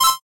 Index of /phonetones/unzipped/Google/Android-Open-Source-Project/notifications/ogg